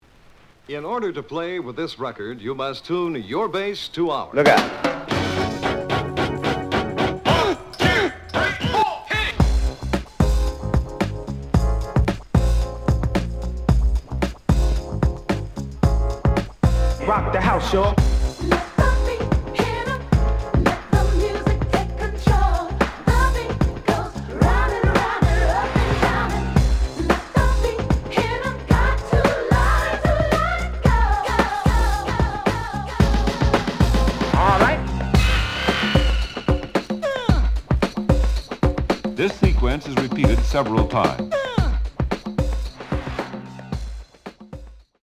The audio sample is recorded from the actual item.
●Genre: House / Techno